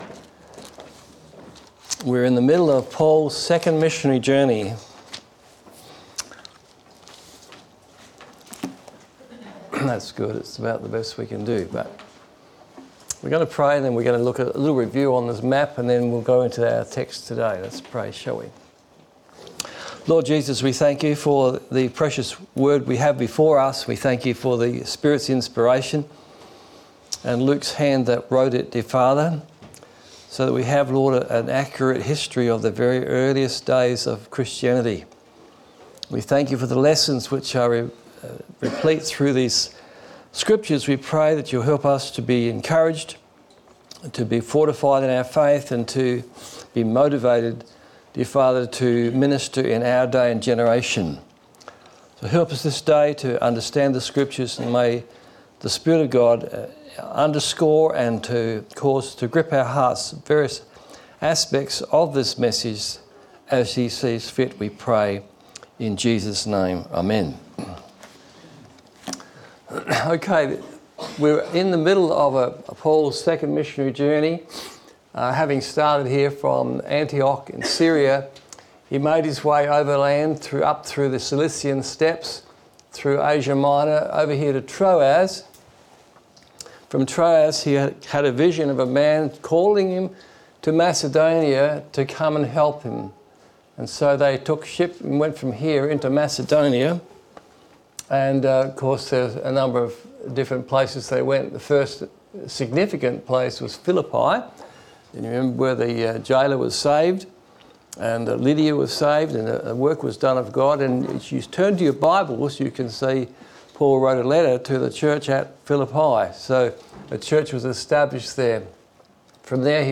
Series: Acts Of The Apostles Service Type: Sunday Morning